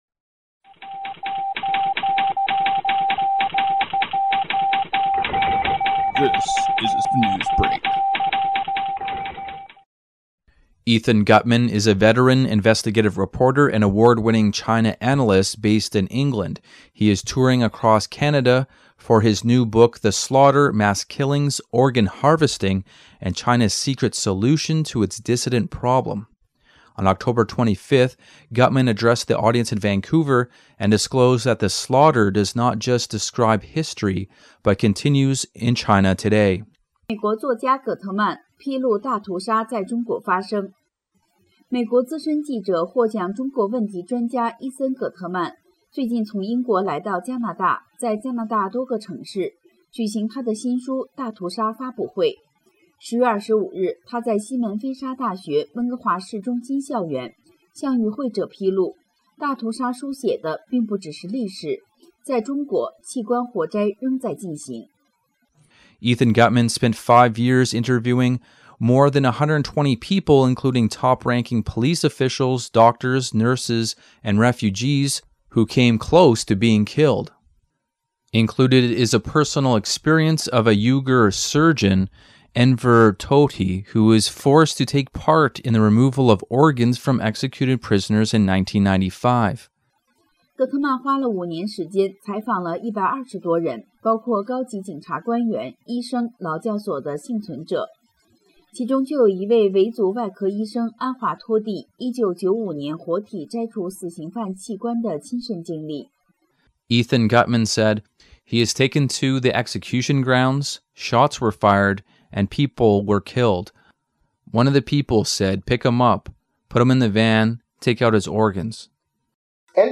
Type: News Reports
128kbps Mono